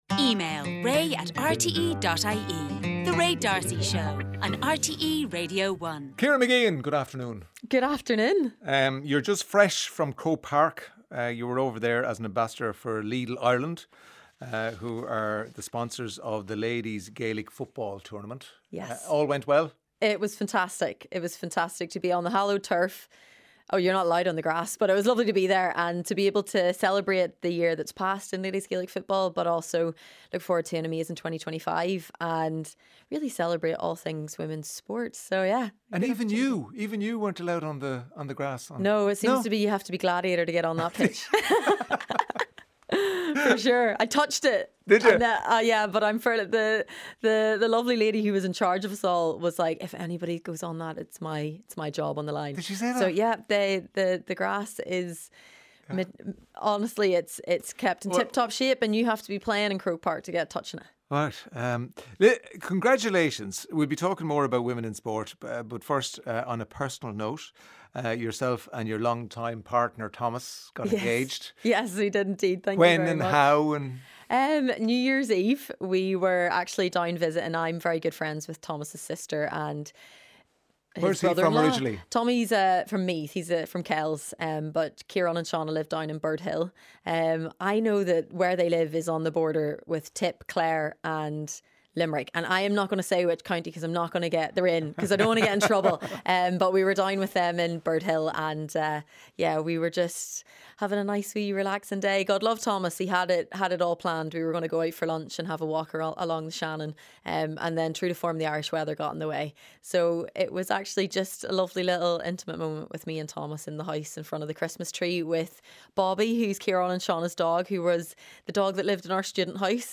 Highlights from the daily radio show with Ray D'Arcy. Featuring listeners' stories and interviews with authors, musicians, comedians and celebrities.